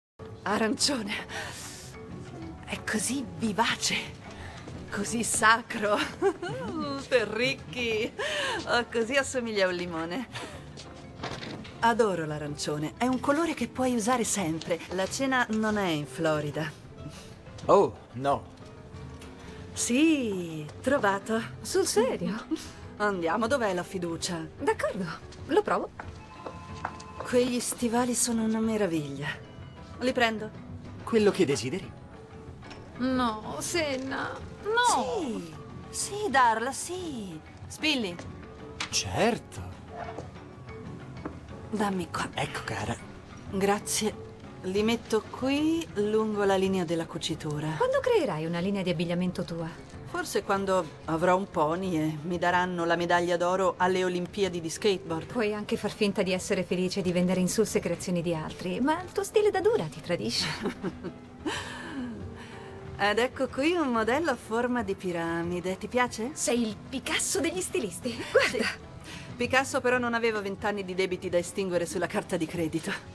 nel film "Quando arriva l'amore", in cui doppia Sharon Stone.